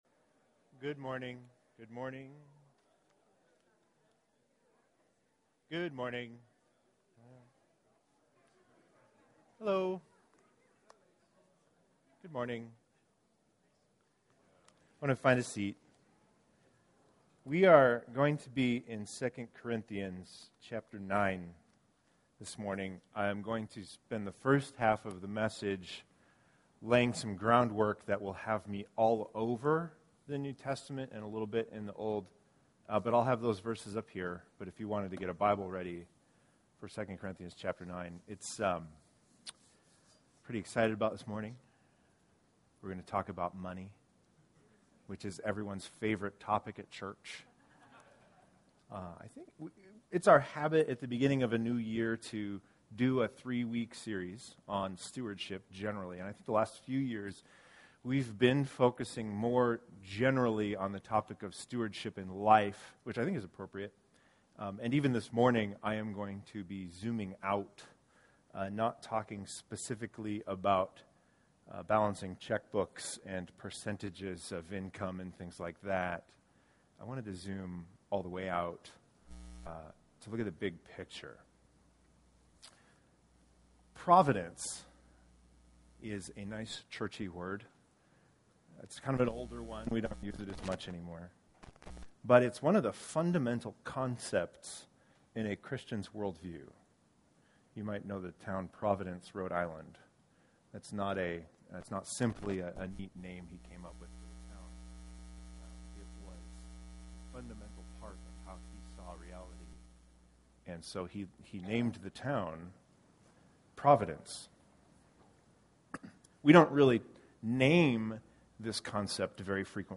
It is our habit, at the beginning of a new year, to begin with a short series of sermons on the topic of stewardship.